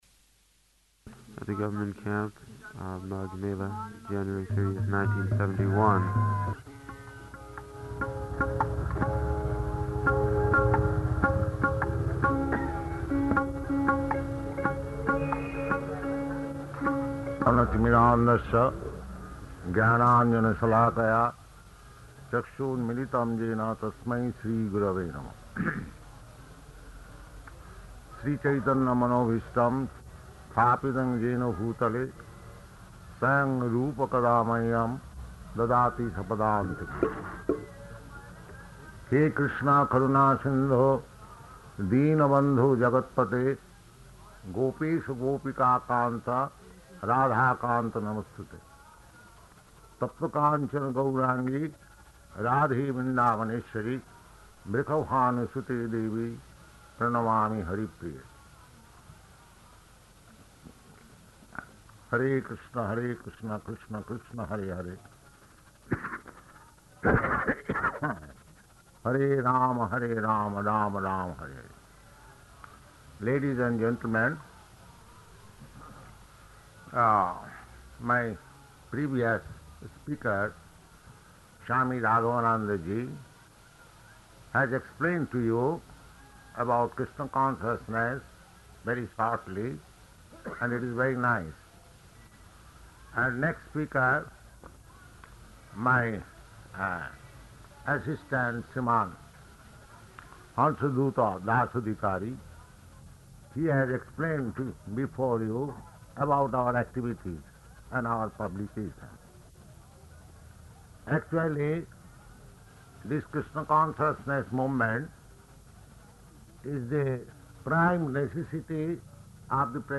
Lecture at Māgha-melā